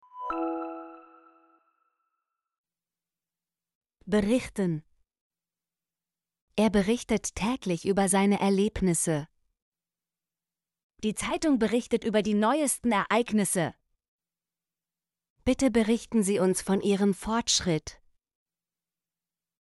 berichten - Example Sentences & Pronunciation, German Frequency List